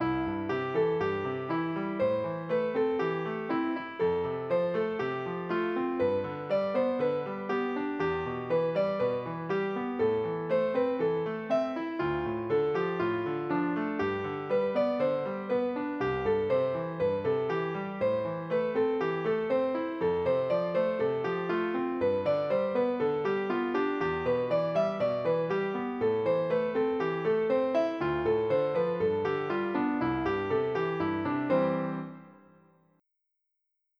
Result: Melody extended from 8 to 16 bars with rhythmic variation and passing tones
piano_extend.wav